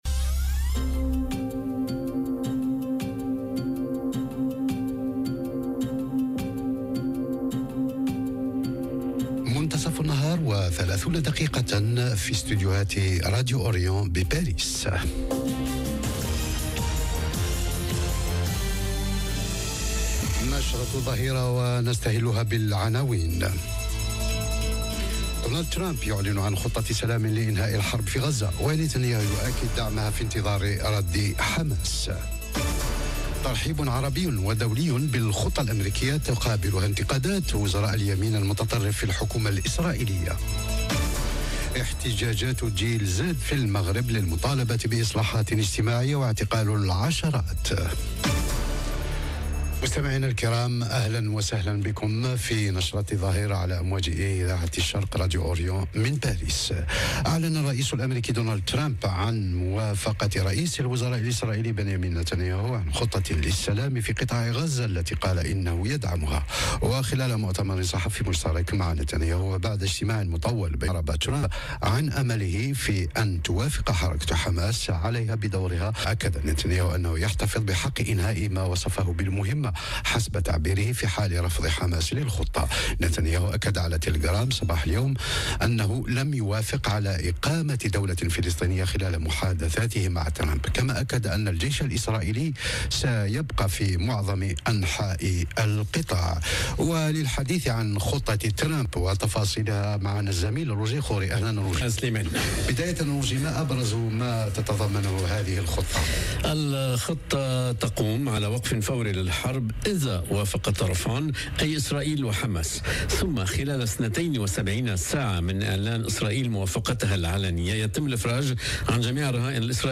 نشرة أخبار الظهيرة: ترامب يعلن عن خطة سلام لإنهاء الحرب في غزة ونتانياهو يؤكد دعمها في انتظار رد حماس - Radio ORIENT، إذاعة الشرق من باريس